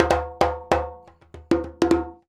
100DJEMB07.wav